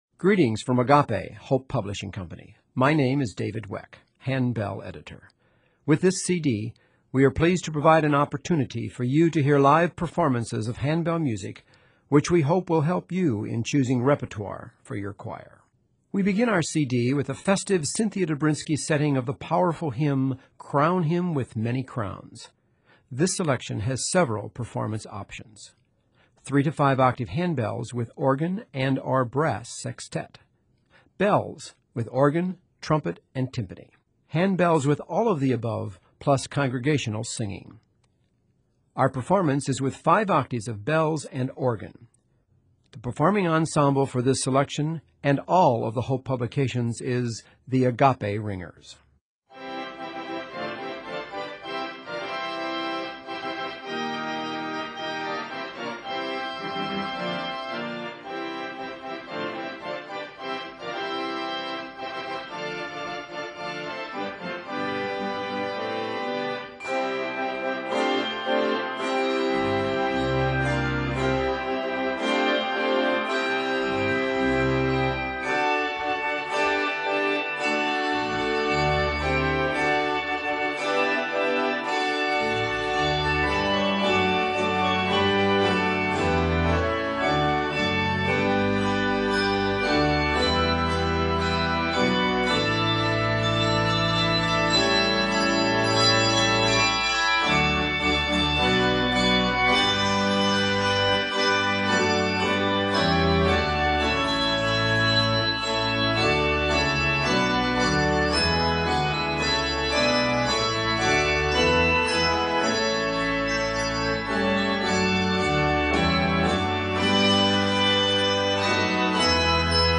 handbell ringing